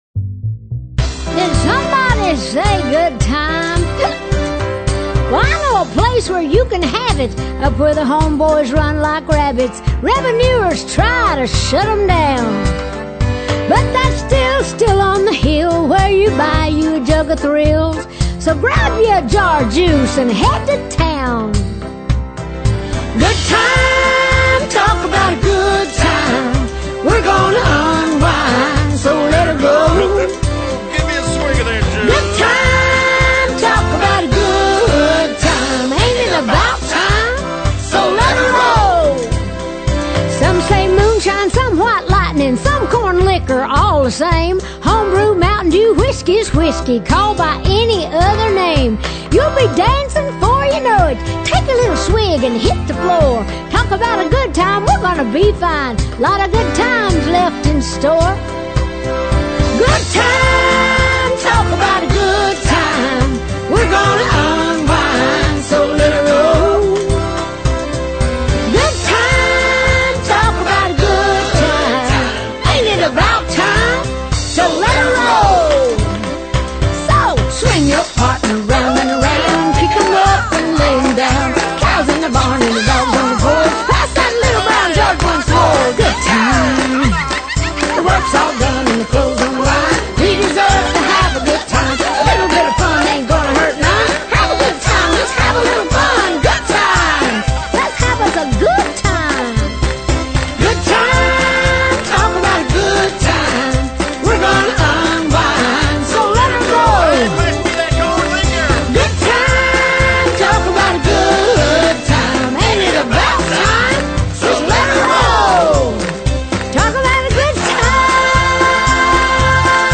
Жанр: Pop, Folk, World, & Country